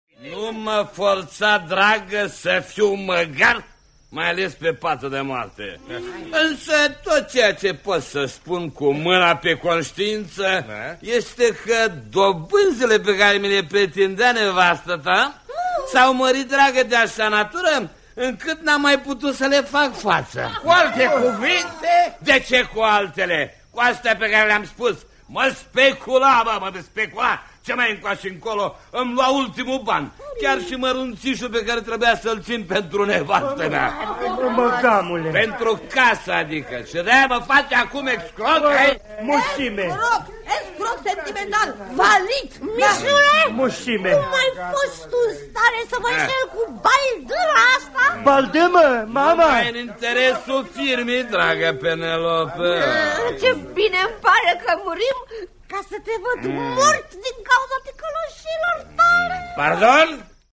Despre poliomielită si vaccinul antipoliomelitic , în interviul următor  :